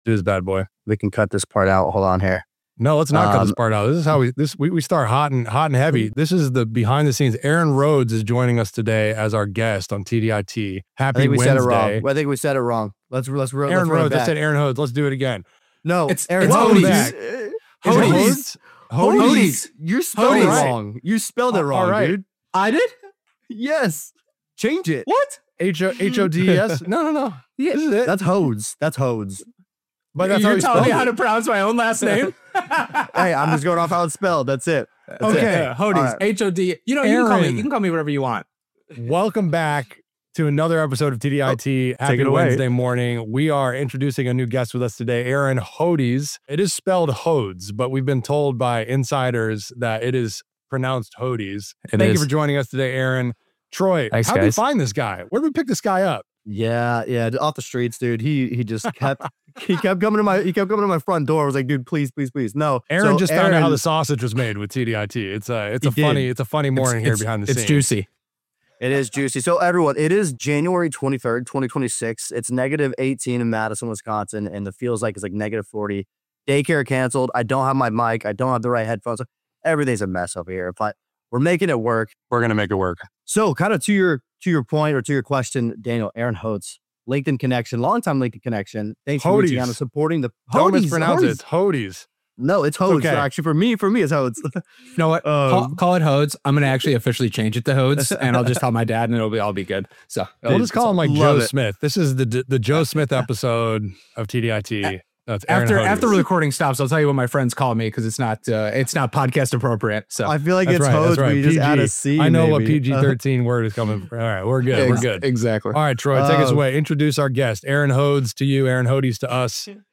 This conversation is raw, reflective, and deeply relatable — especially for dads, professionals, and anyone quietly carrying more than they let on.